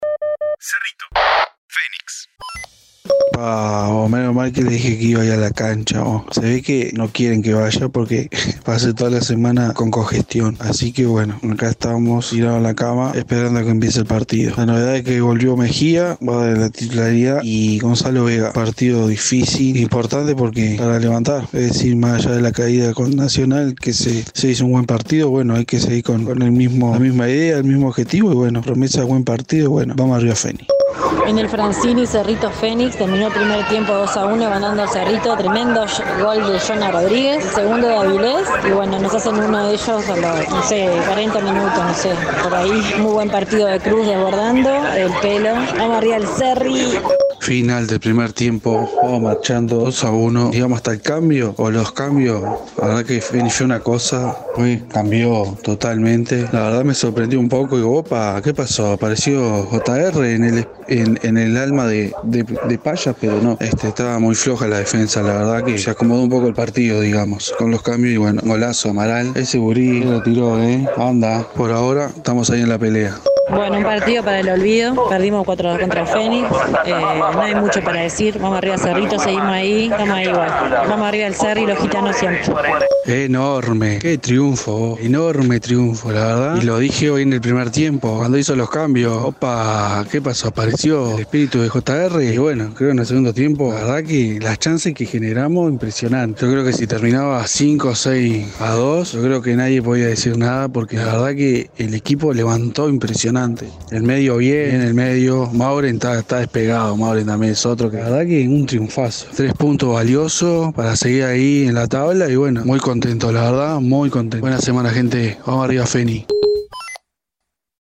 Los partidos de la sexta fecha del Torneo Clausura del fútbol uruguayo vistos y comentados desde las tribunas.